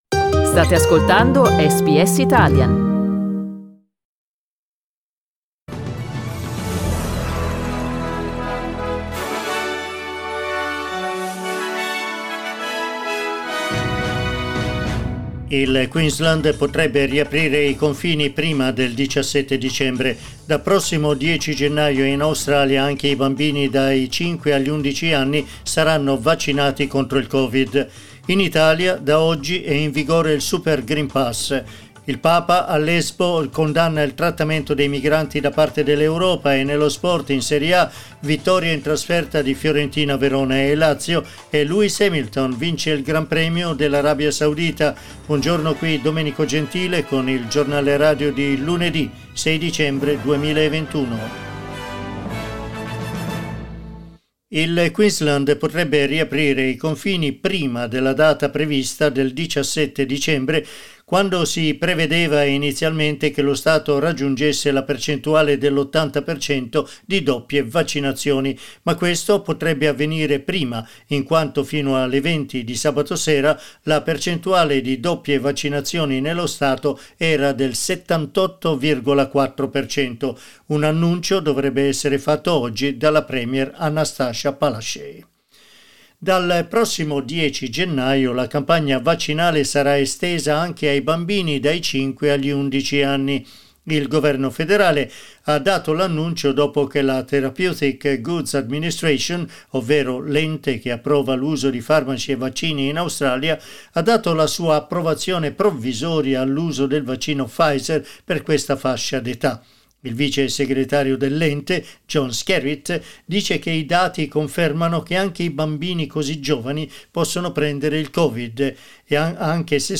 Giornale radio lunedì 6 dicembre 2021
Il notiziario di SBS in italiano.